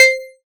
edm-perc-19.wav